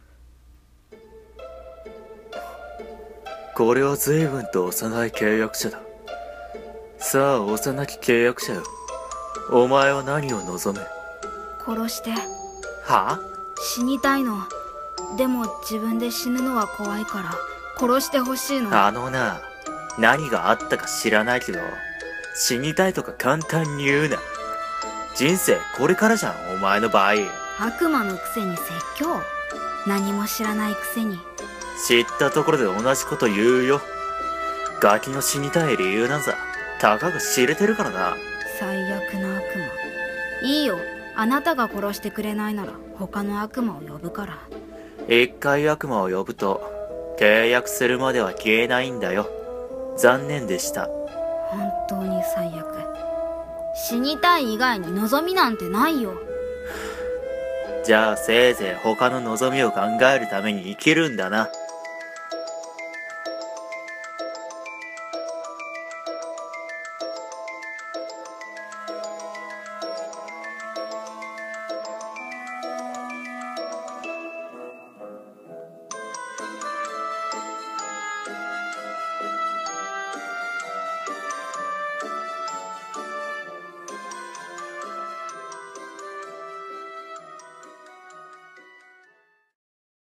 声劇 悪魔と契約者 掛け合い